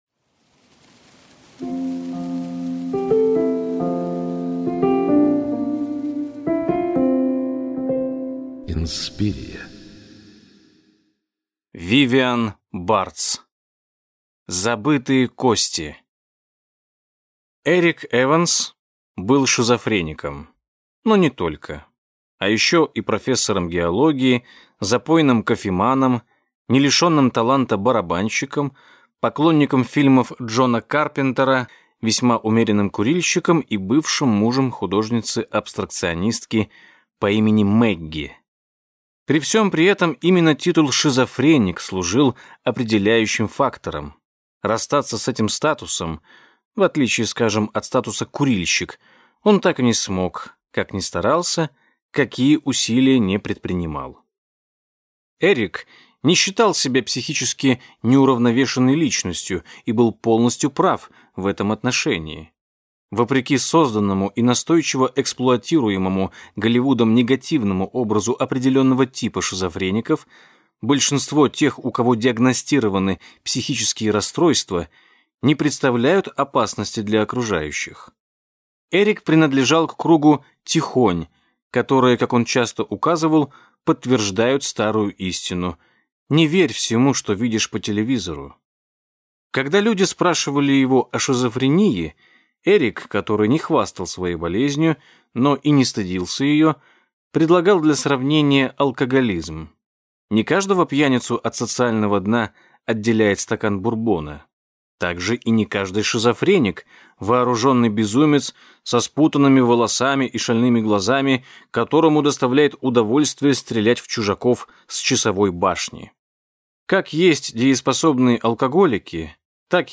Аудиокнига Забытые кости | Библиотека аудиокниг